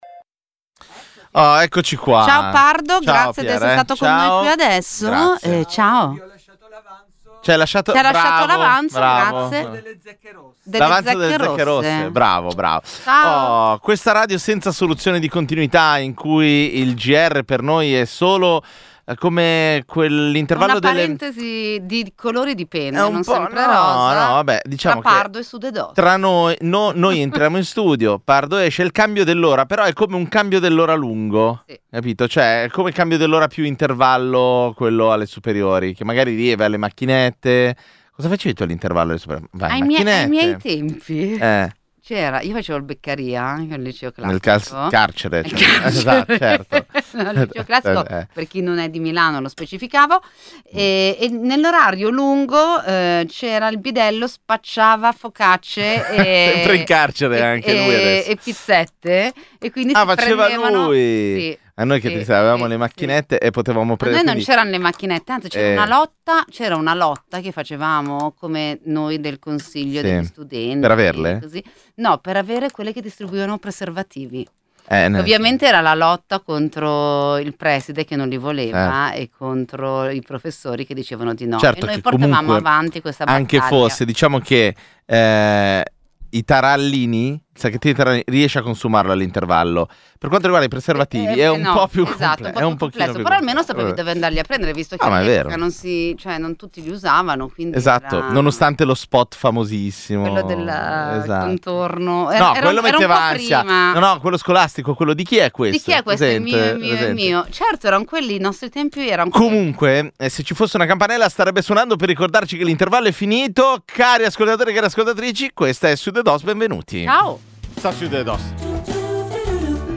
Sudedoss è il programma di infotainment che ogni domenica sera dalle 19.45 alle 21.30 accompagna le ascoltatrici e gli ascoltatori di Radio Popolare con leggerezza, ironia e uno sguardo semiserio sull’attualità.